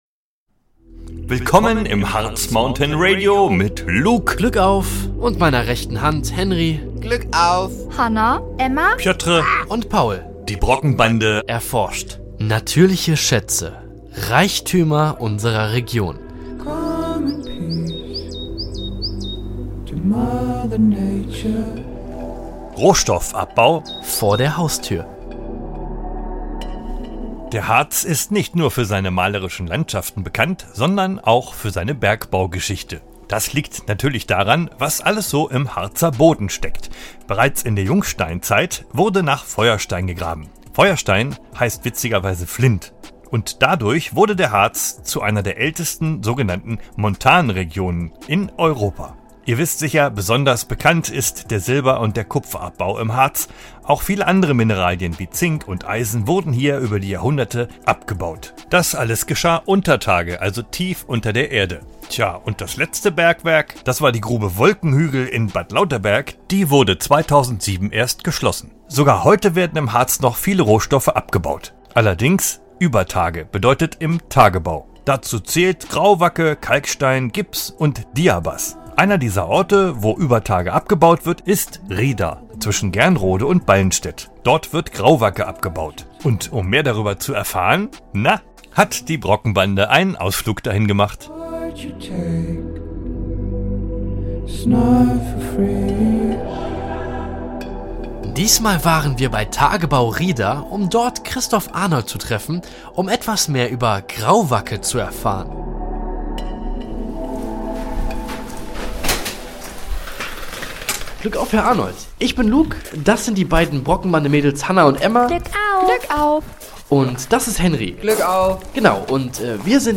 In dieser spannenden Folge begleitet die Brockenbande euch direkt in den großen Grauwacke-Tagebau von Rieder – mitten im Harz und im Herzen einer jahrhundertealten Bergbautradition.